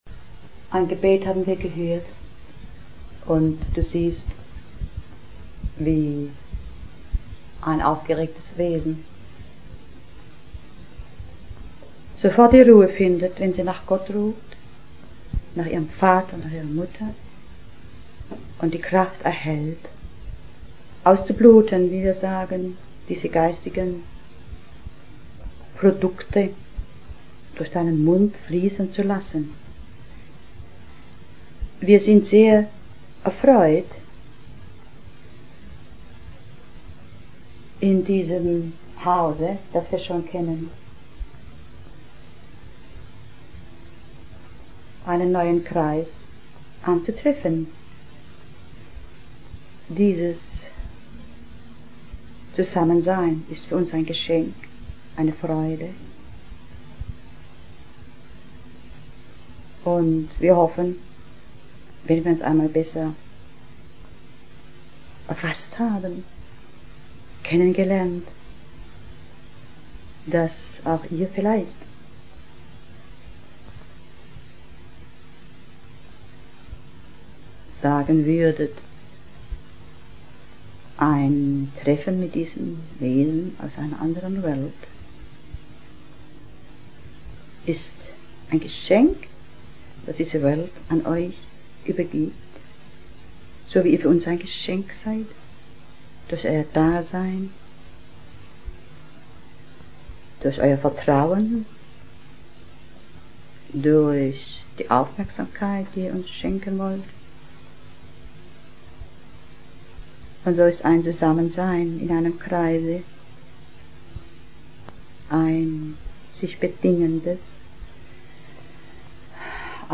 42. Vortrag
42. Bandaufnahme in Ravensburg22. Oktober 1982